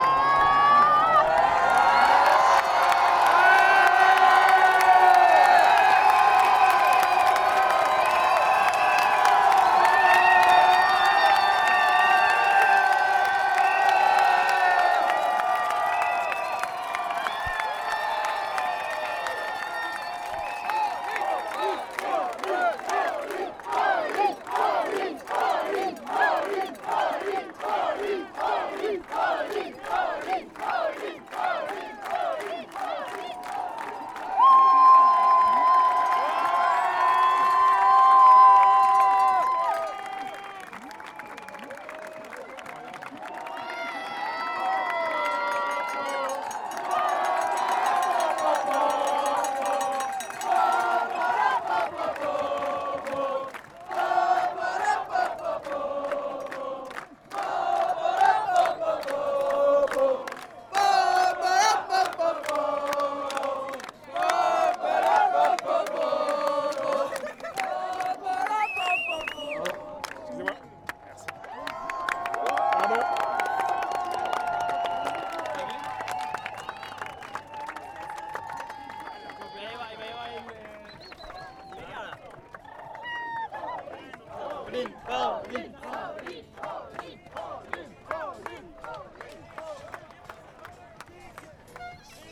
Sfeer en supporters: een onvergetelijk moment
Wie kippenvel krijgt van aanmoedigingen en gezang, kan zich met deze audio-opname – die gemaakt werd op zondag – onderdompelen in de sfeer alsof je er zelf bij was.